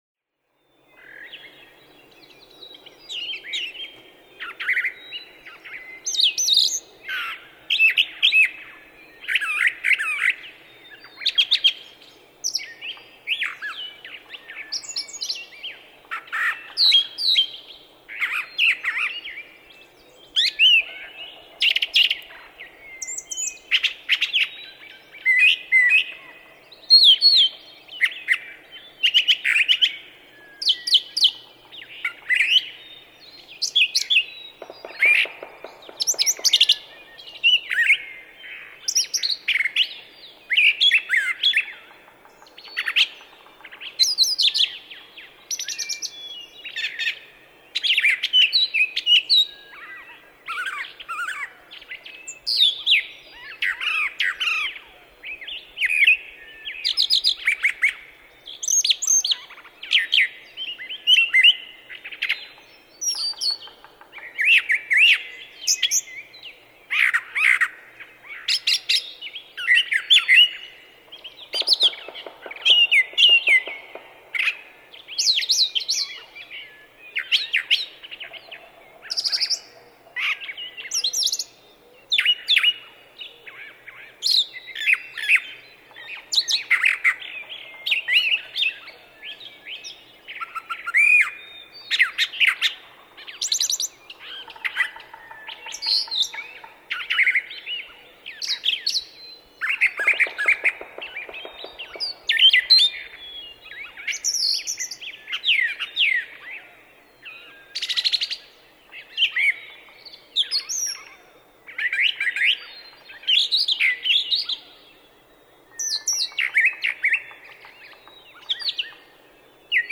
Brown thrasher
♫235. Song matching during early spring countersinging, example 1; these two males match each other at least 49 times in this eight-and-a-half minute session.
235_Brown_Thrasher.mp3